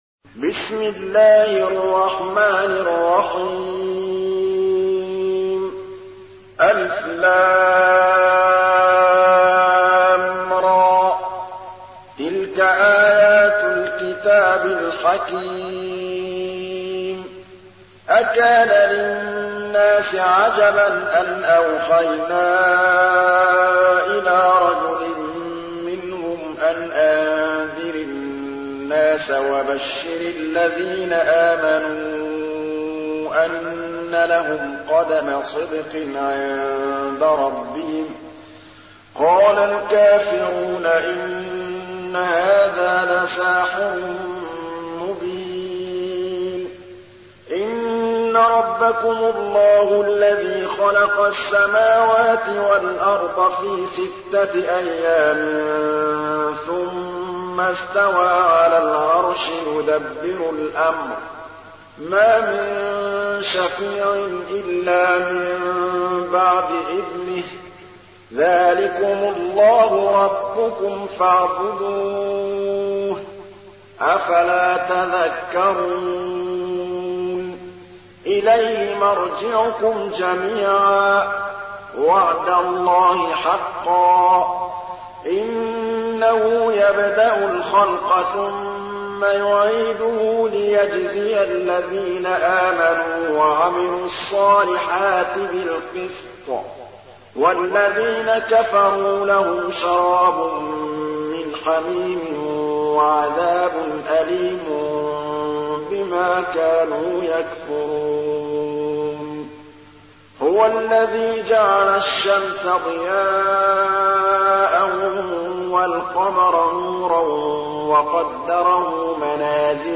تحميل : 10. سورة يونس / القارئ محمود الطبلاوي / القرآن الكريم / موقع يا حسين